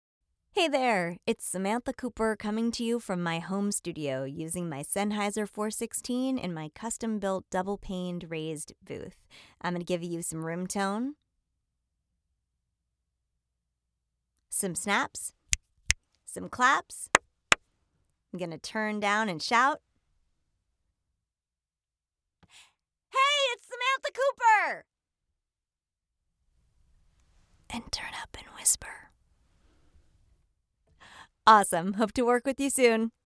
Explainer
Female
TEENS, 20s, 30s
American English (Native)
Bright, Bubbly, Character, Cheeky, Children, Confident, Engaging, Friendly, Natural, Warm, Witty, Versatile, Young, Authoritative
Microphone: Sennheiser MKH 416, Warm Audio 87 (Neumann clone)
Audio equipment: custom-built booth, Steinberg UR242, Source Connect